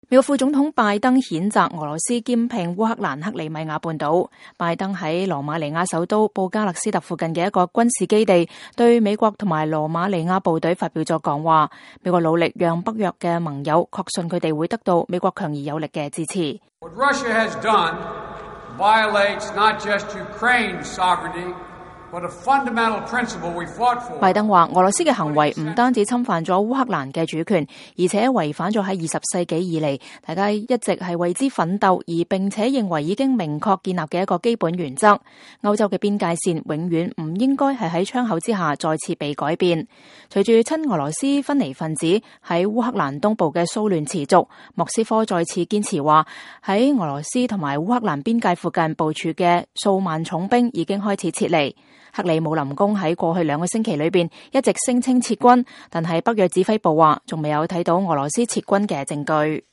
美國副總統拜登譴責俄羅斯兼併烏克蘭克里米亞半島。拜登在羅馬尼亞首都布加勒斯特附近一個軍事基地對美國和羅馬尼亞部隊發表了講話。